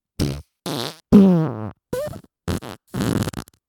FART SOUND 94